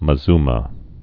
(mə-zmə)